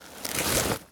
foley_sports_bag_movements_05.wav